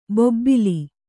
♪ bobbili